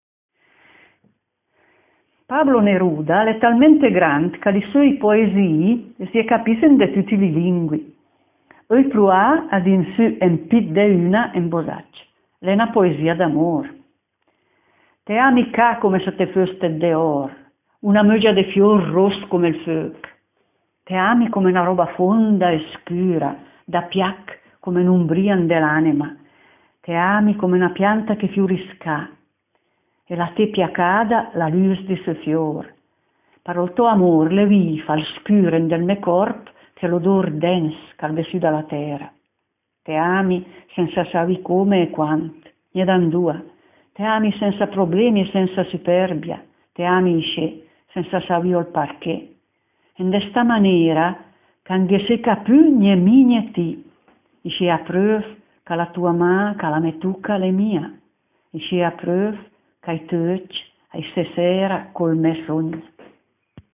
Poesia d'amor | Dialetto di Albosaggia
Pablo Neruda l’è talméet grant, ca li söi poesii sè li capìss en de tüti li lingui; öi pruàa a dìn sǜ 'n pìt de üna en bosàc’.